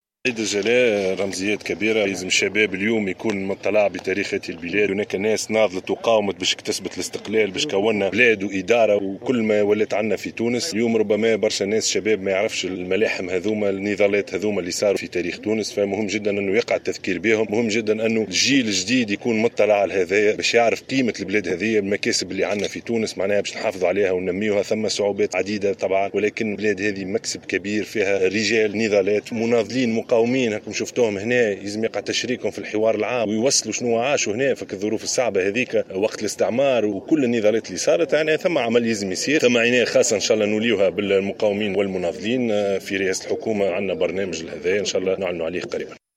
En marge de la commémoration du 54ème anniversaire de la fête de l’évacuation, le chef du gouvernement a assuré qu’il faut valoriser davantage les militants de la nation et de leur accorder la place qu’ils méritent au sein de la société.